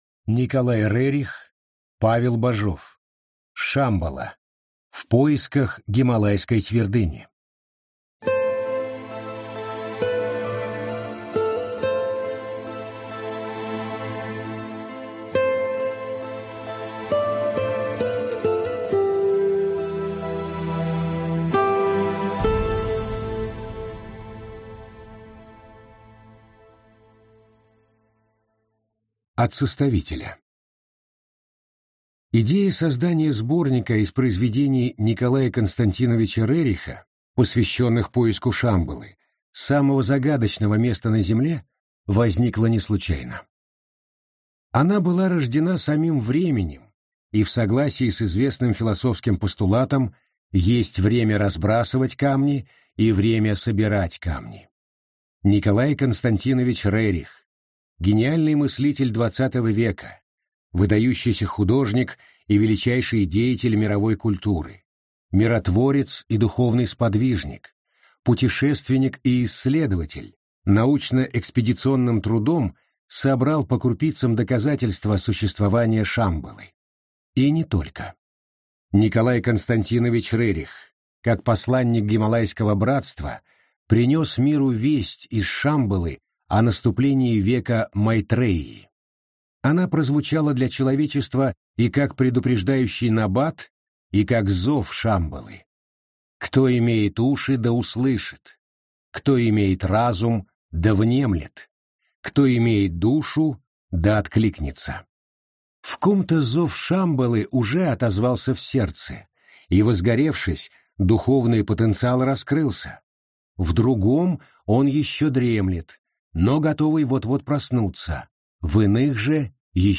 Аудиокнига Шамбала. В поисках Гималайской Твердыни. Дорогое имячко | Библиотека аудиокниг